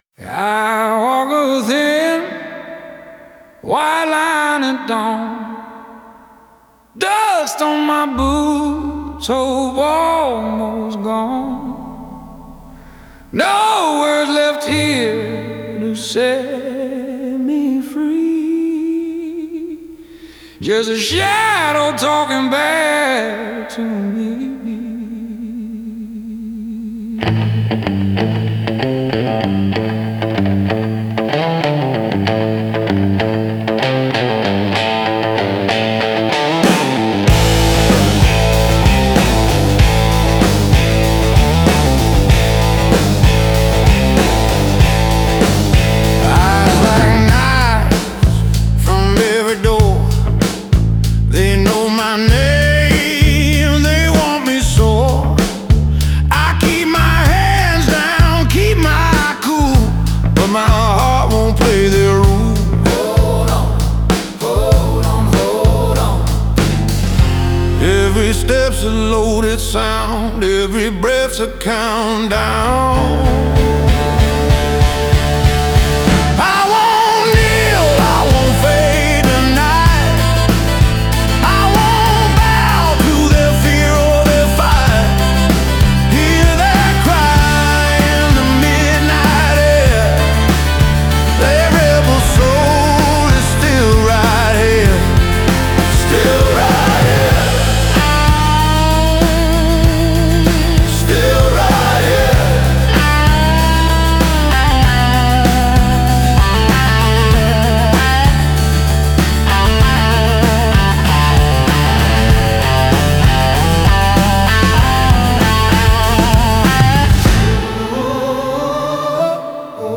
オリジナル曲♪
曲が進むにつれ、リズムと感情が徐々に解放され、主人公は恐れから逃げるのではなく、正面から受け止める覚悟を固めていく。